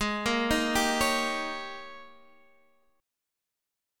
AbM7sus2sus4 Chord